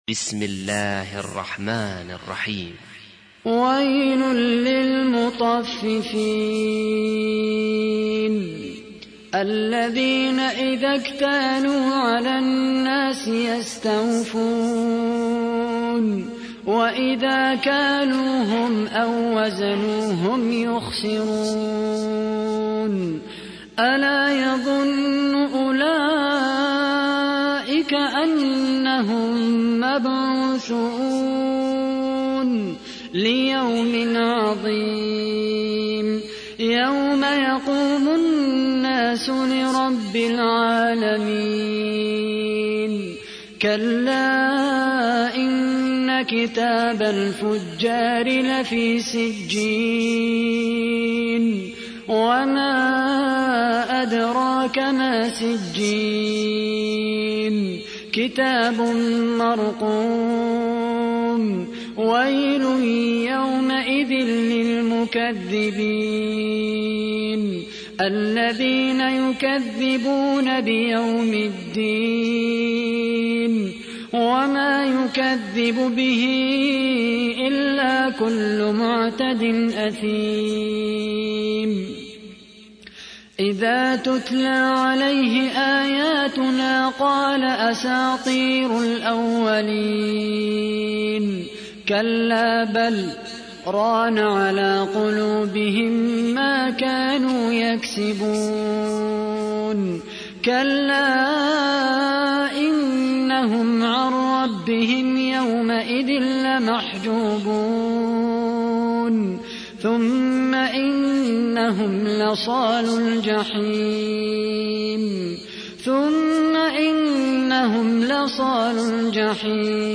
تحميل : 83. سورة المطففين / القارئ خالد القحطاني / القرآن الكريم / موقع يا حسين